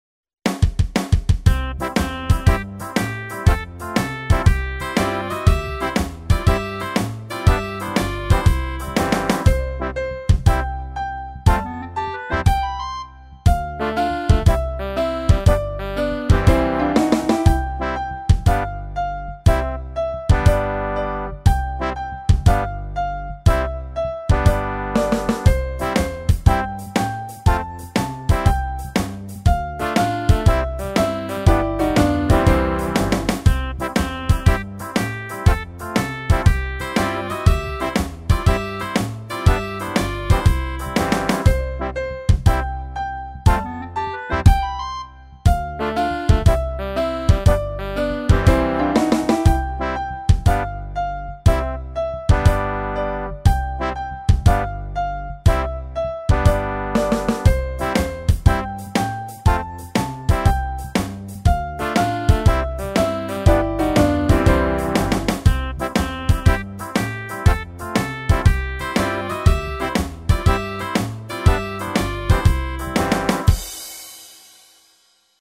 - aranżacje do ćwiczeń gry na dzwonkach: